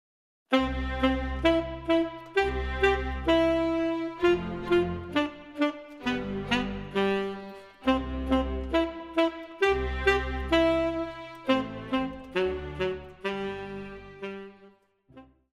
古典
管弦樂團
演奏曲
僅伴奏
沒有主奏
有節拍器